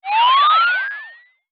radio_tune.wav